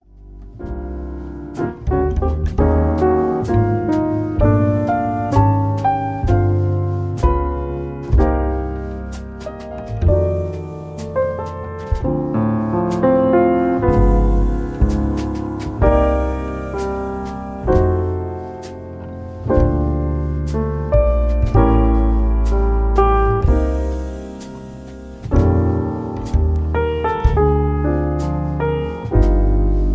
karaoke